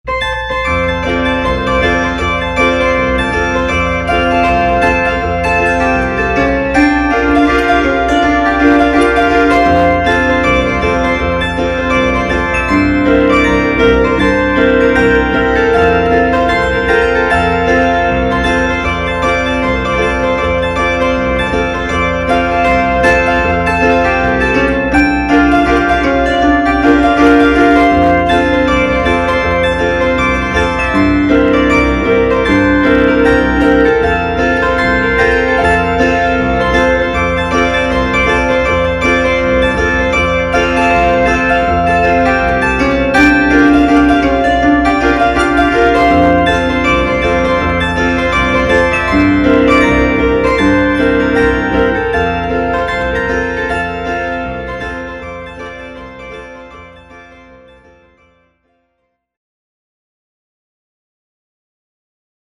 Phonoharp 4/30 chord-zither (altered)